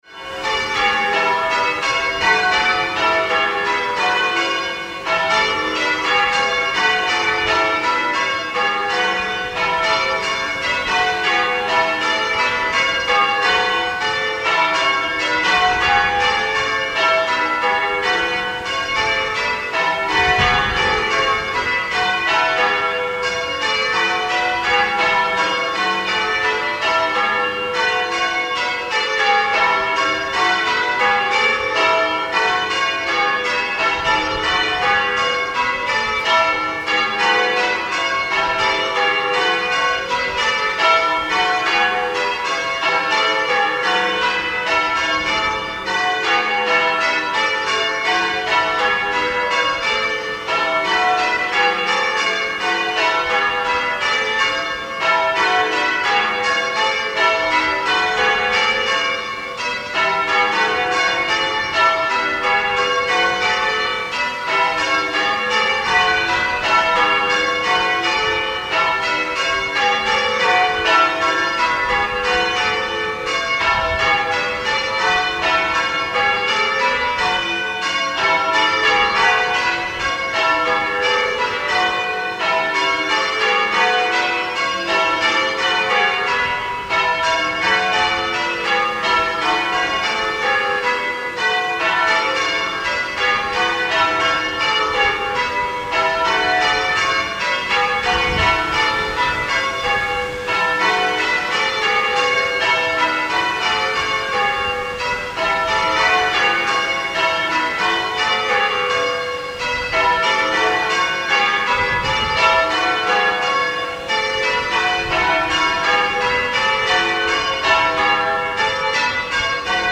6 bells 14-0-25 in  E Excellent going order
Peal Records Ground floor ring.
Extract from a peal of two minor methods; Cambridge Surprise Minor and Plain Bob Minor, The ringing in the recorded extract is Cambridge Surprise Minor. The peal was rung on Ascension Day, 29th May 2025, marking the 50th anniversary of the collapse of the tower on Ascension Day 1975.